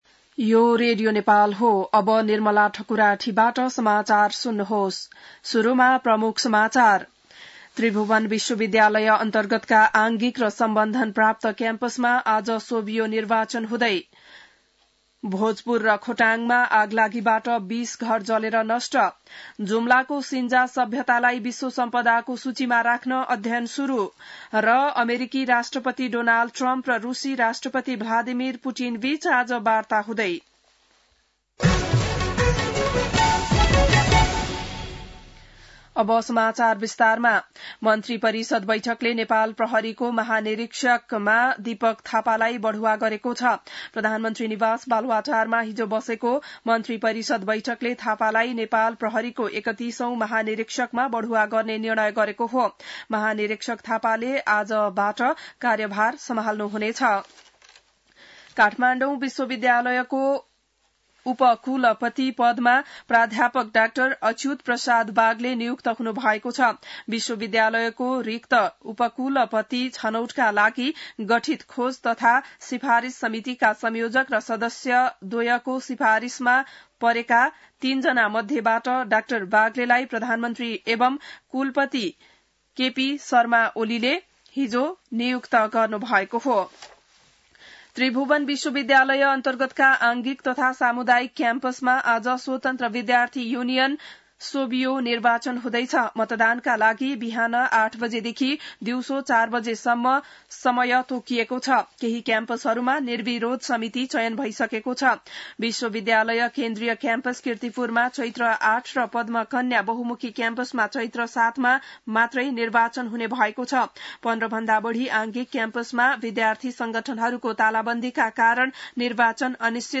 बिहान ९ बजेको नेपाली समाचार : ५ चैत , २०८१